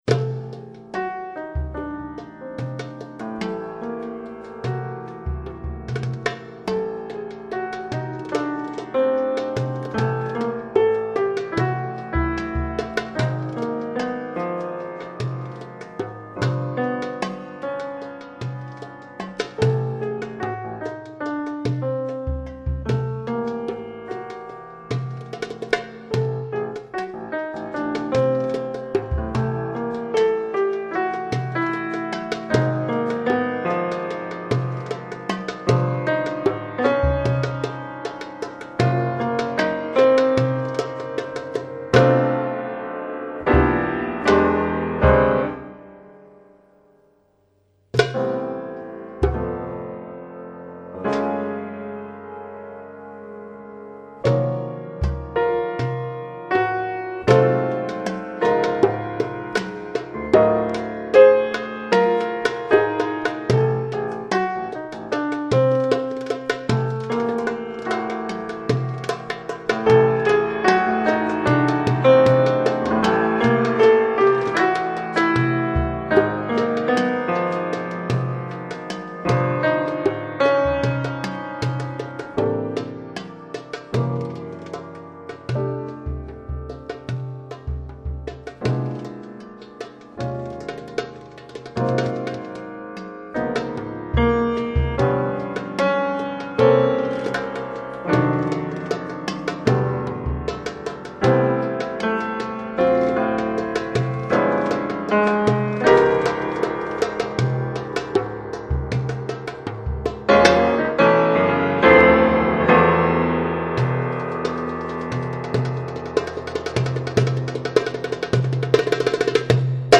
piano
percussions